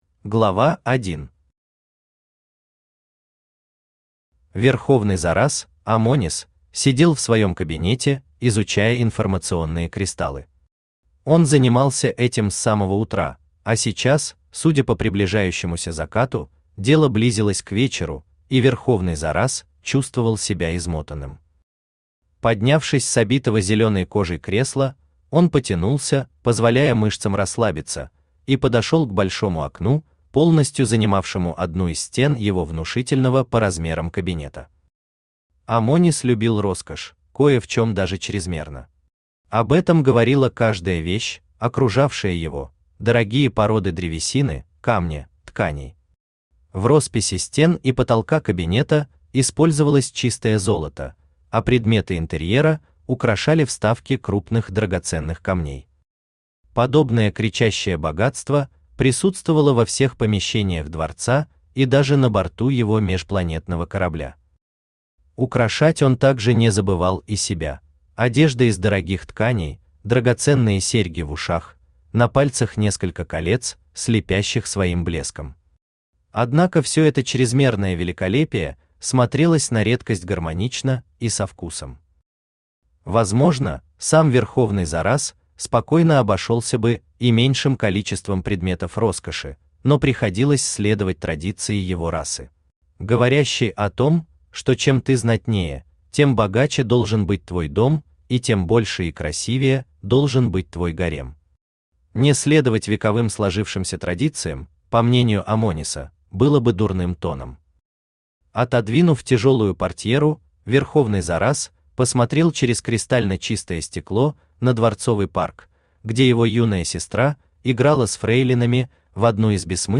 Аудиокнига Империя. Наследие владык | Библиотека аудиокниг
Наследие владык Автор Иван Лебедин Читает аудиокнигу Авточтец ЛитРес.